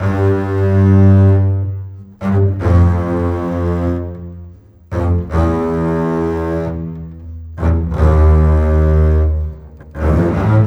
Rock-Pop 07 Bass 01.wav